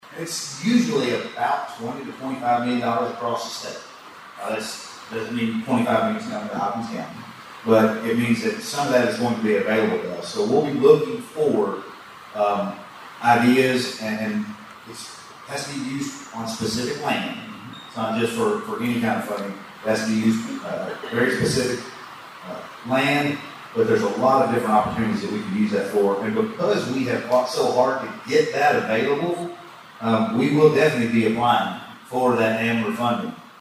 Local leaders are actively advocating in Frankfort and Washington, D.C., to secure funding for economic growth, veteran support, and infrastructure projects, which was one of the topics discussed at last week’s State of the Cities and County event.